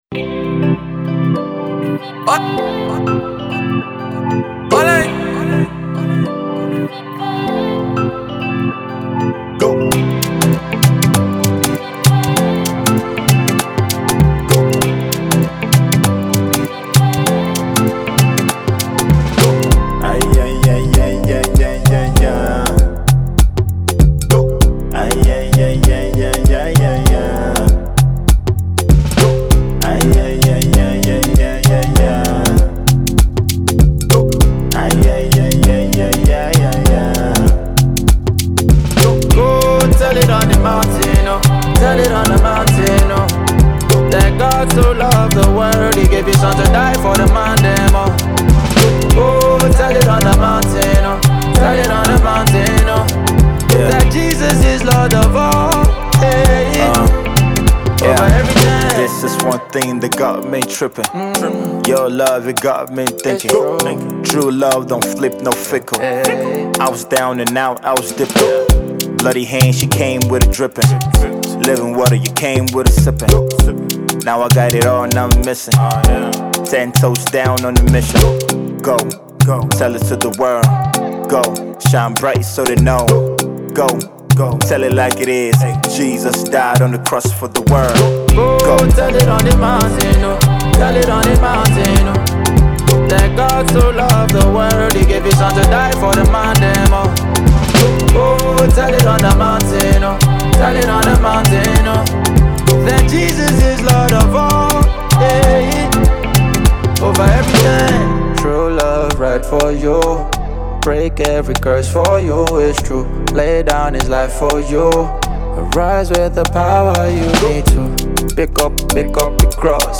Christian rapper